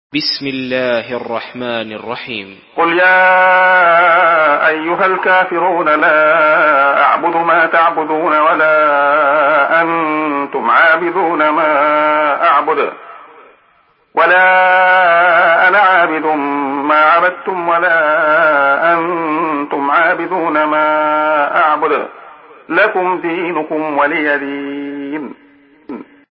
Surah Al-Kafirun MP3 by Abdullah Khayyat in Hafs An Asim narration.
Murattal Hafs An Asim